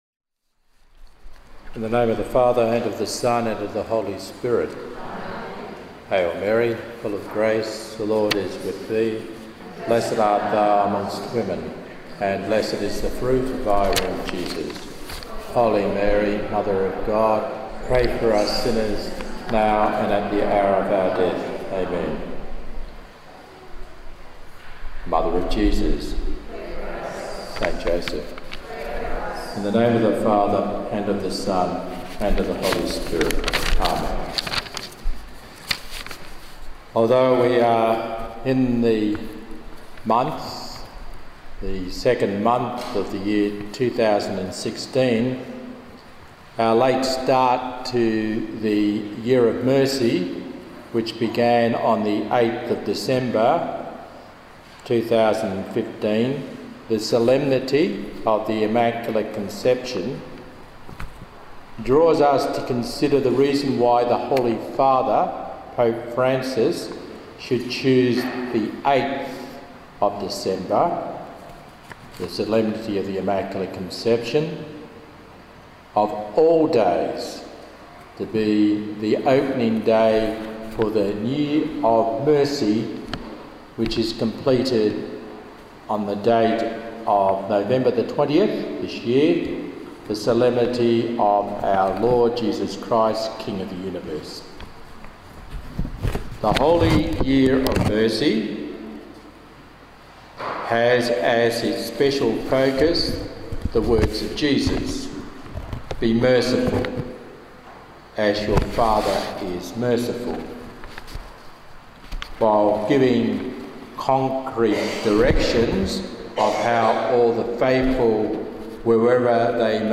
held at Our Lady Queen of Martyrs Parish in Maylands, Western Australia on 6 February 2016